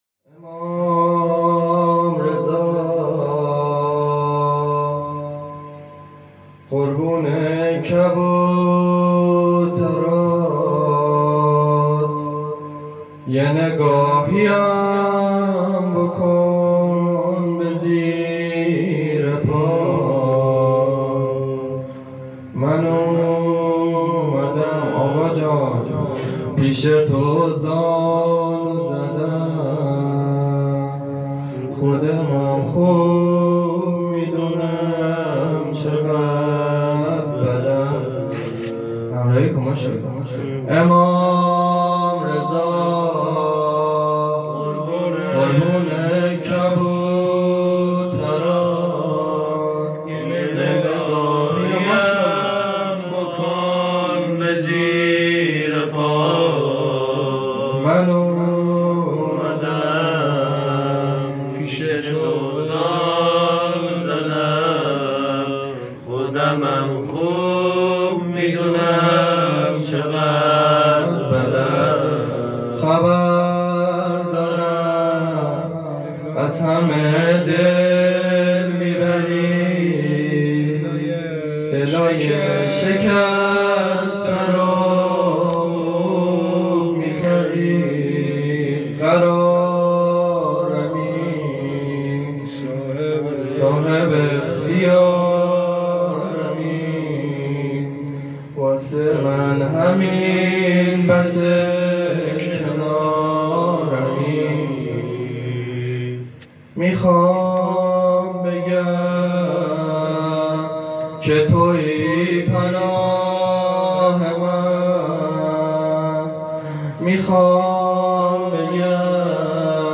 مجلس روضه هفتگی | 99/11/23 | هیئت کاروان حسینی (ع)